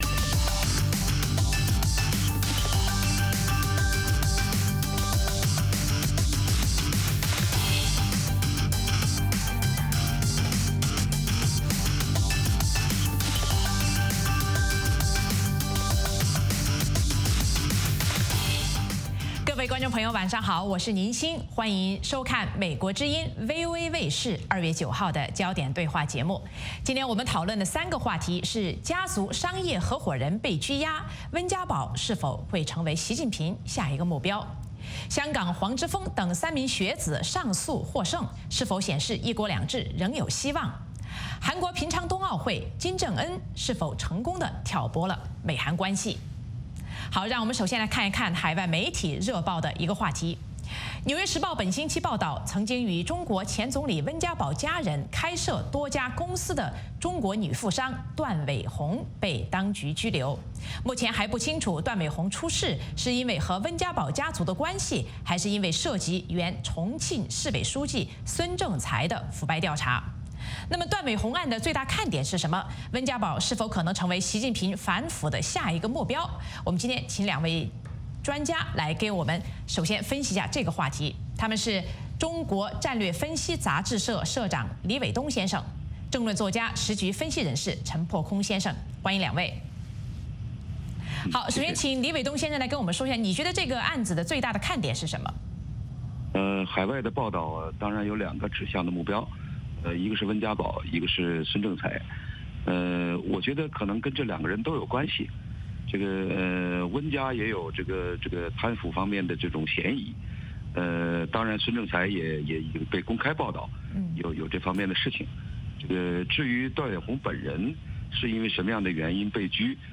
《焦点对话》节目追踪国际大事、聚焦时事热点。邀请多位嘉宾对新闻事件进行分析、解读和评论。